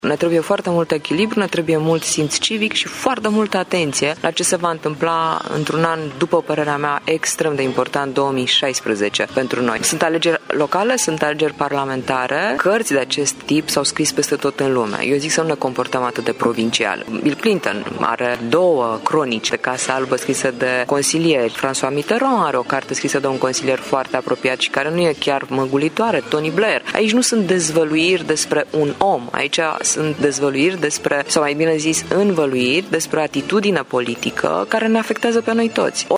Astfel de cronici au mai fost scrise în lume, spune Adriana Săftoiu, iar românii ar trebui să fie mai echilibrați în privința politicienilor, mai ales că anul viitor urmează alegeri: